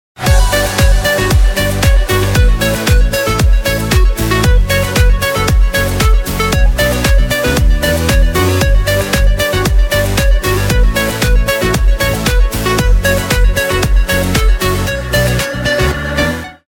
رینگتون پرانرژی و بیکلام